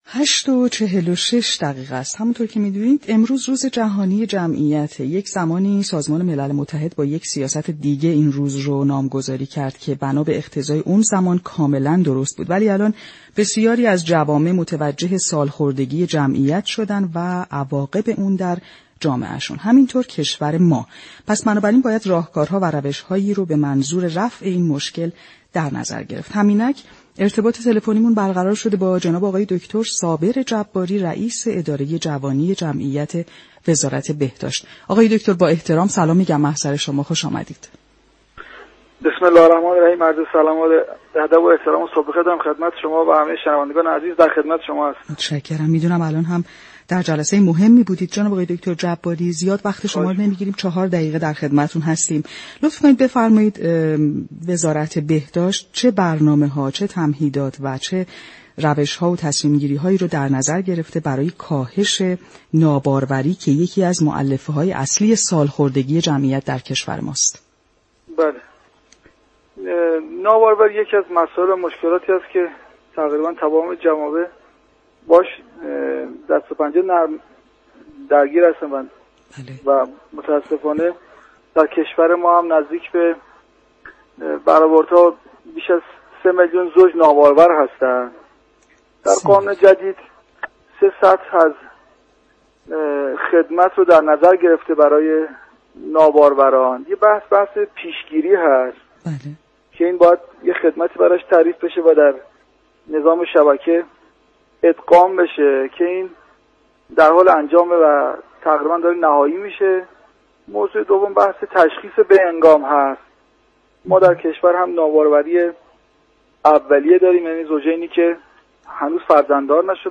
گفت و گو با برنامه تهران ما سلامت